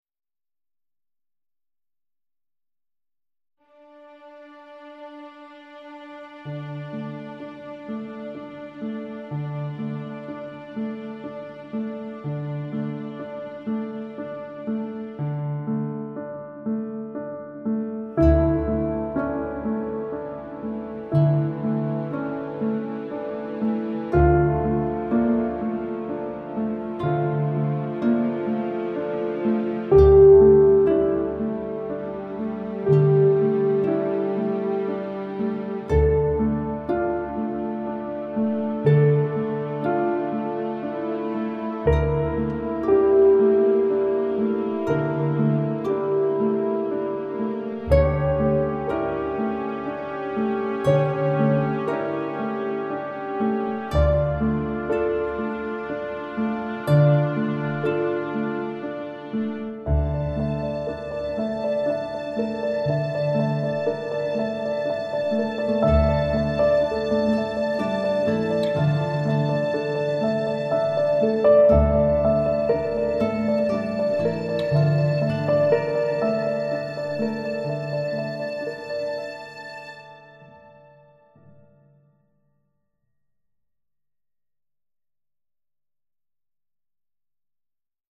it’s romantic moody and tense as is the music .